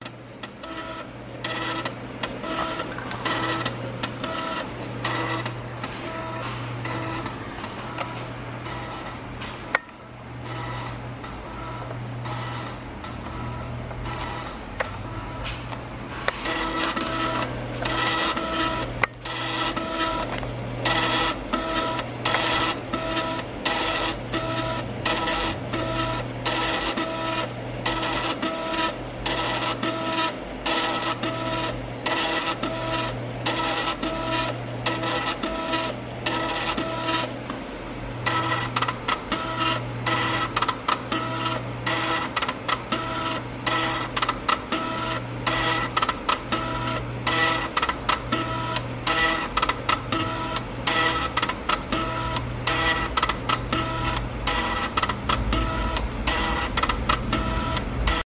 Звук дискеты в дисководе (FDD, Floppy Disk Drive)